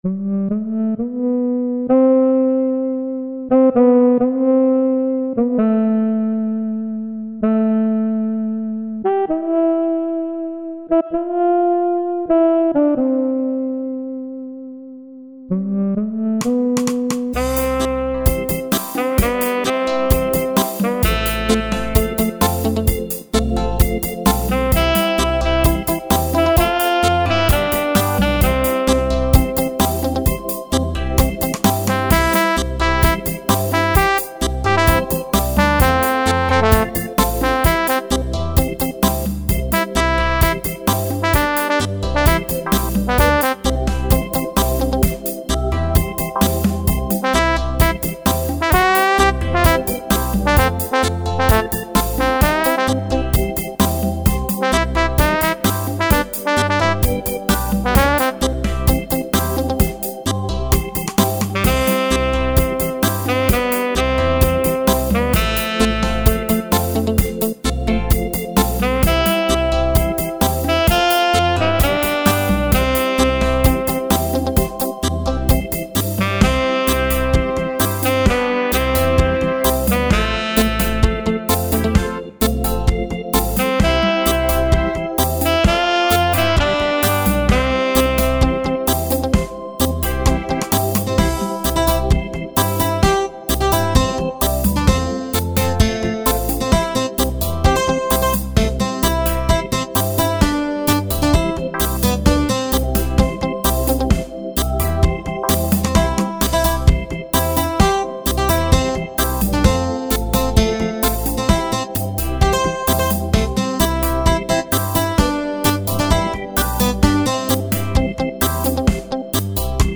Jamaican Pop Reggae